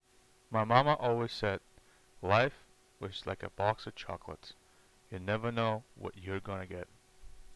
Microphone experience was a pleasant one as well. You are not going to get studio type of performance with Cloud Stinger but for gaming and skyping I think it will do the job.